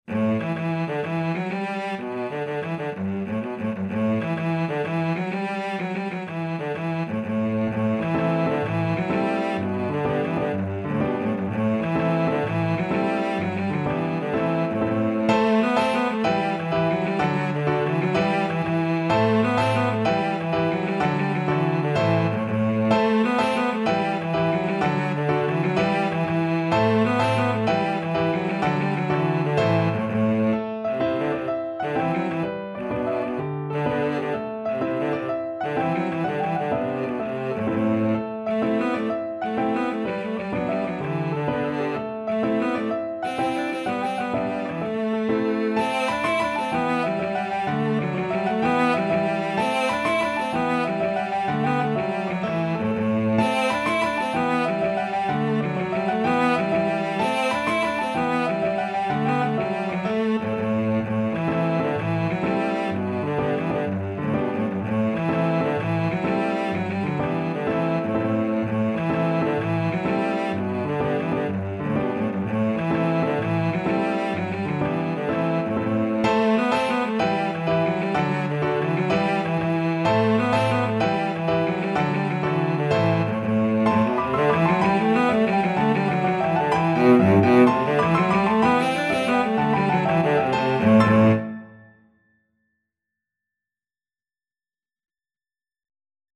Cello
Traditional Music of unknown author.
E minor (Sounding Pitch) (View more E minor Music for Cello )
Fast .=c.126
12/8 (View more 12/8 Music)
Irish